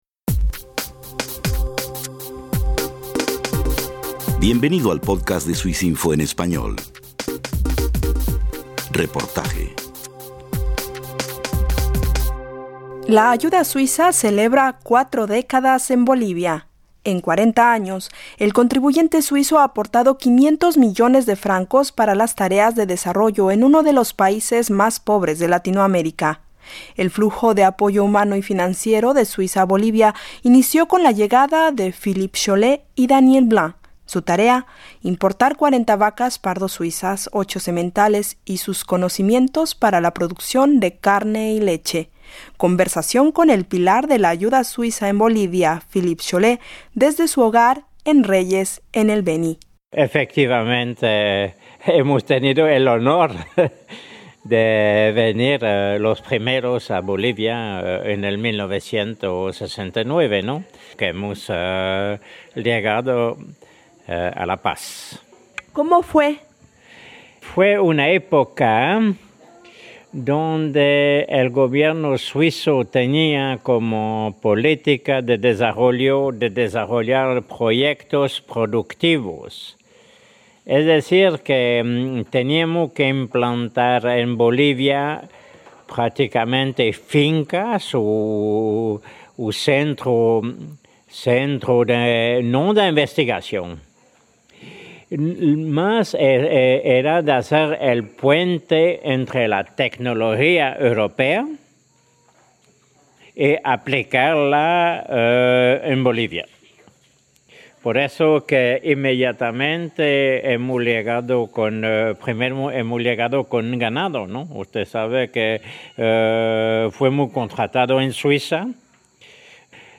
Desde Reyes, el Beni